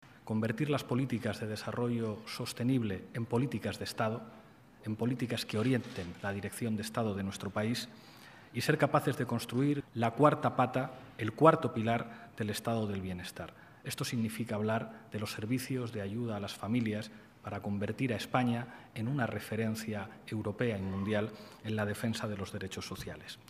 manifestó formato MP3 audio(0,36 MB) el vicepresidente 2º del Gobierno, Pablo Iglesias, el pasado 22 de enero, en la toma de posesión de los altos cargos de su departamento, comenzando por sus dos nuevos secretarios de Estado, Nacho Álvarez, titular del área de Derechos Sociales, e Ione Belarra, secretaria de Estado de la Agenda 2030, las dos patas del Ministerio que Iglesias dirige con rango de Vicepresidencia.